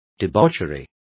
Also find out how libertinaje is pronounced correctly.